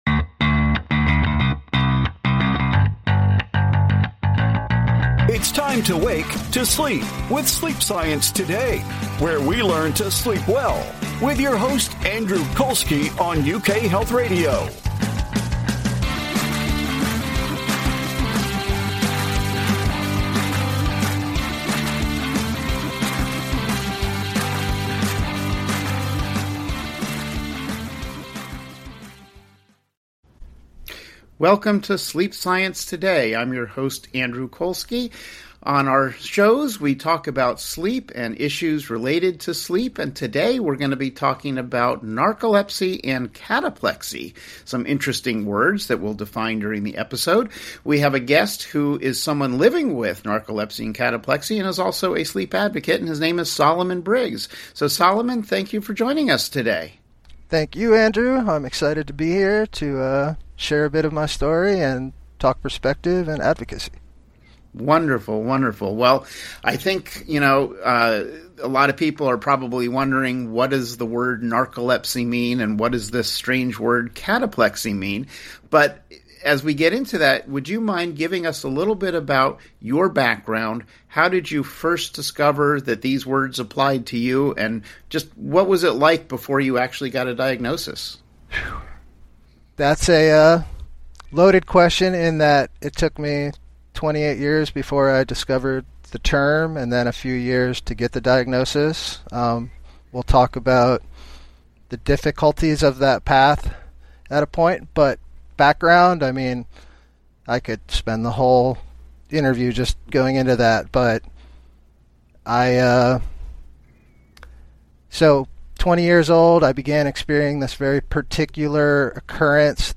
Doctors have learned that restful sleep is critical for your physical and mental health. You will hear from renowned sleep experts as they share the latest information about how to sleep better with science.